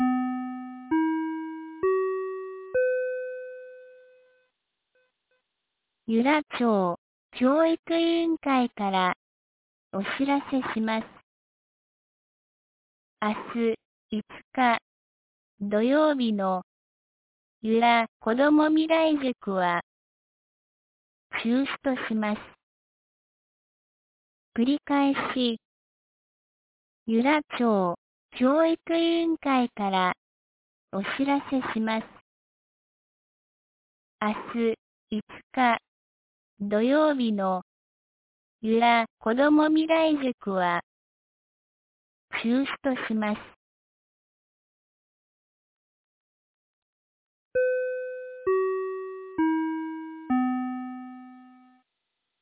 2022年11月04日 17時15分に、由良町から横浜地区、里地区、南地区、阿戸地区、網代地区、江ノ駒地区、門前地区、中地区、畑地区へ放送がありました。